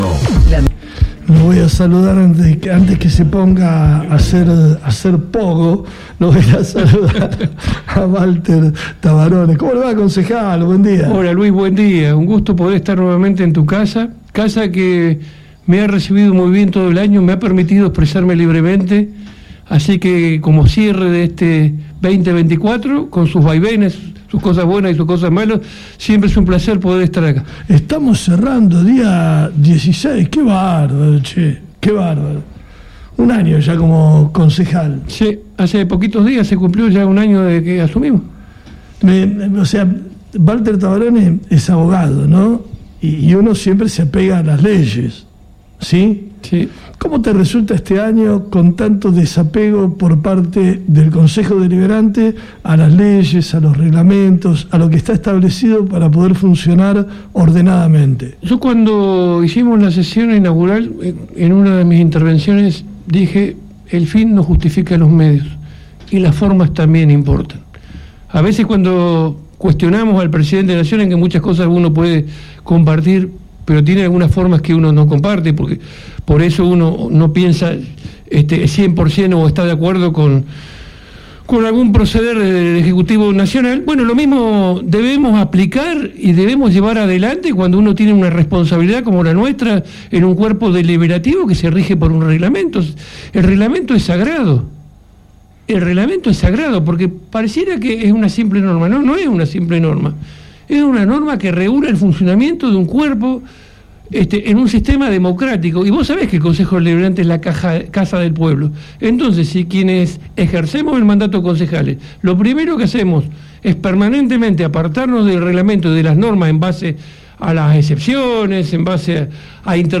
Entrevistamos en FM Ártika al concejal de Somos Fueguinos, Valter Tavarone.
Tras un año intenso en el Concejo Deliberante de Ushuaia, y luego de la última y polémica sesión del jueves pasado, el edil de Somos Fueguinos, Valter Tavarone, visitó los estudios de FM Ártika para analizar lo actuado en su primer año como concejal. La dura tarea de ser oposición y las deudas pendientes que deja este primer año de gestión, tanto municipal como el cuerpo que integra, fueron algunos puntos tratados en la extensa charla en la radio.